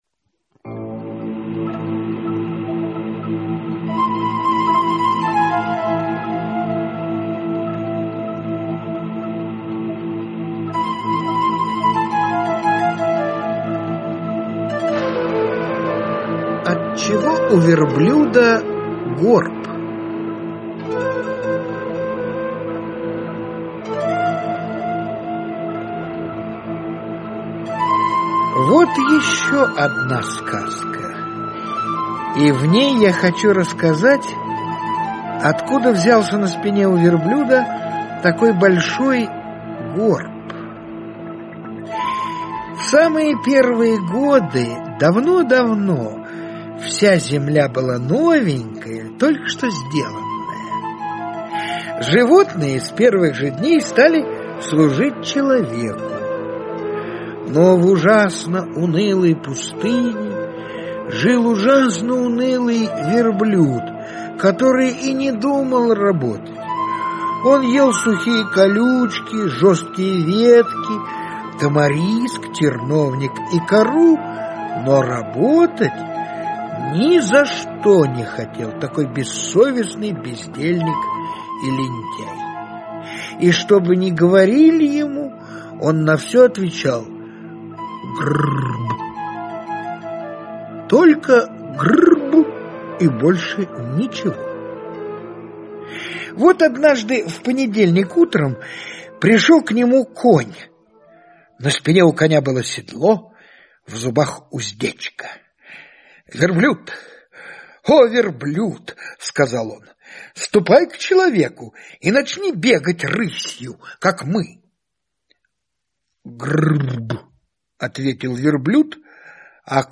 На данной странице вы можете слушать онлайн бесплатно и скачать аудиокнигу "Отчего у верблюда горб" писателя Редьярд Киплинг.